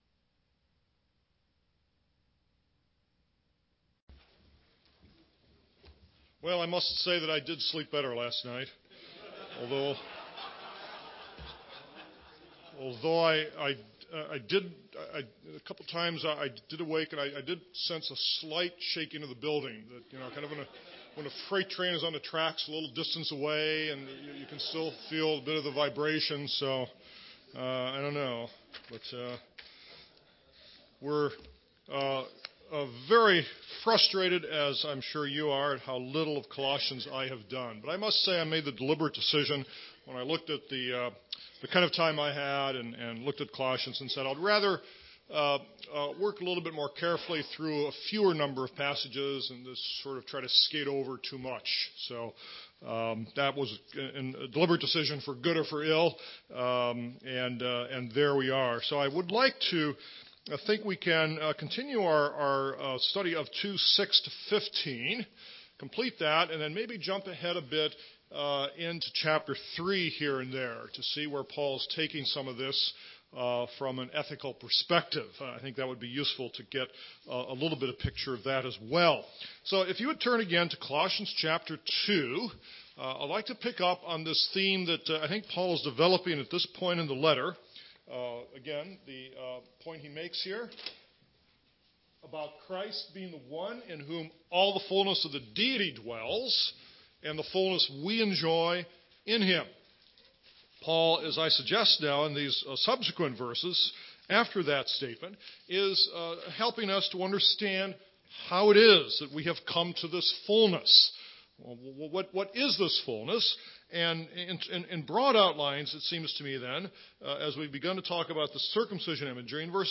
In this sermon, the preacher discusses the concept of fullness in the new experience of deliverance from the power of sin. He emphasizes that through our identification with Christ, we are given the ability to live a new life and become a new people.